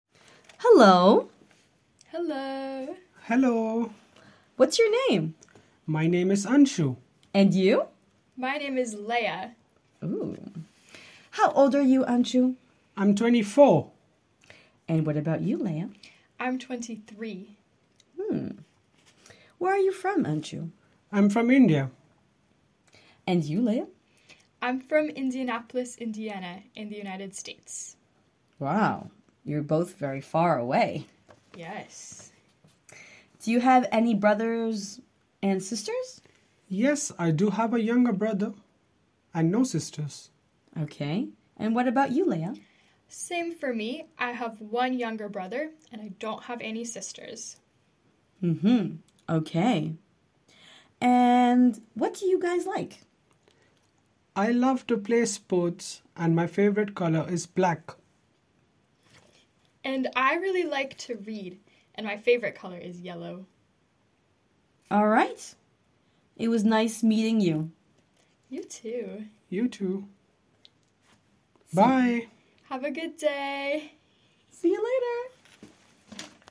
dialogue de présentation